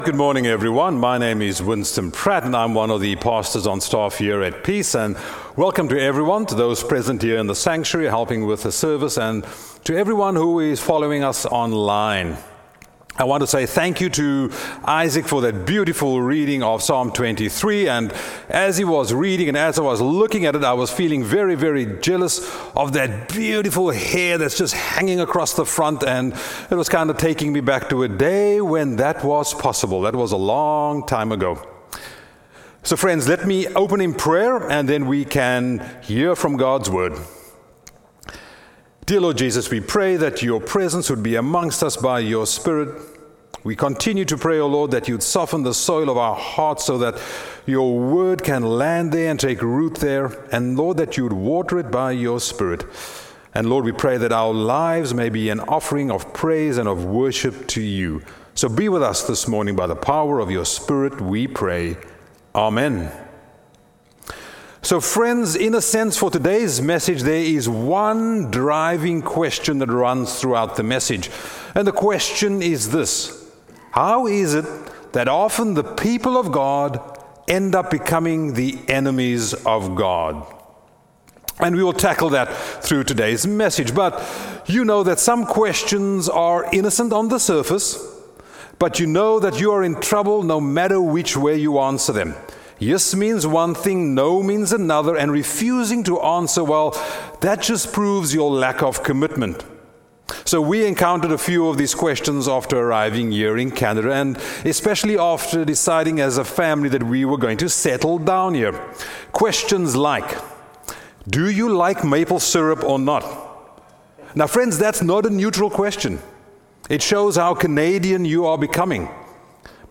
Sermons | Peace Mennonite Church